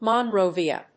/ˌmɑˈnroviʌ(米国英語), ˌmɑ:ˈnrəʊvi:ʌ(英国英語)/